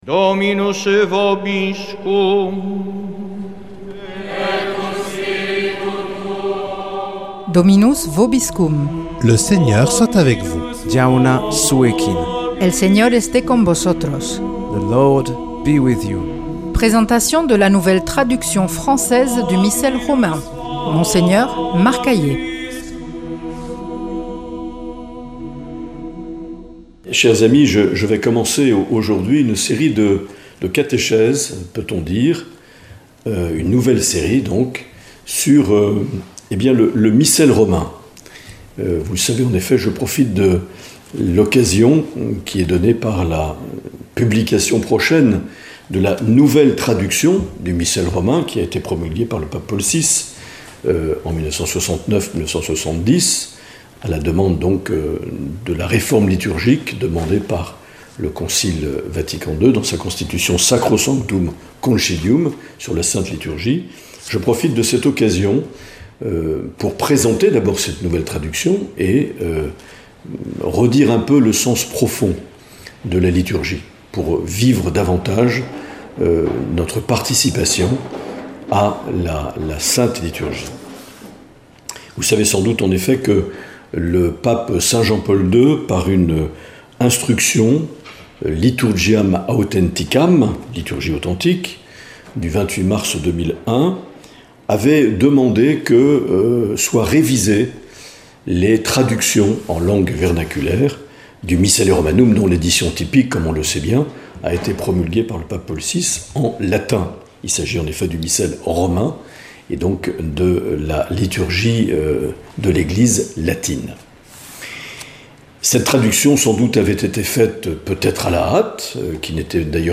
Présentation de la nouvelle traduction française du Missel Romain par Mgr Marc Aillet
Monseigneur Marc Aillet